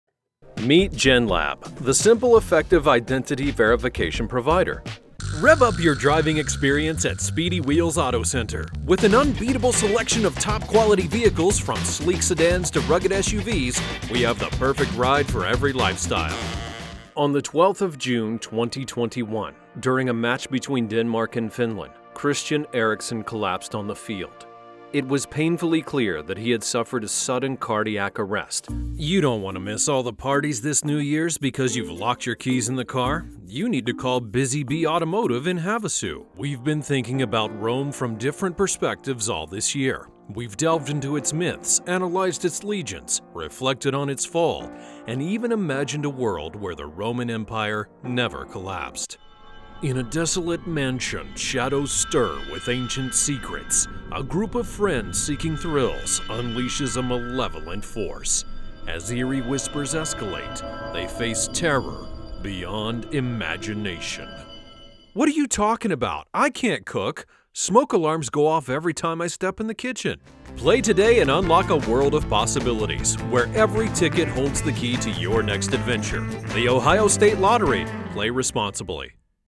English (American)
Deep, Natural, Friendly, Warm, Corporate
Commercial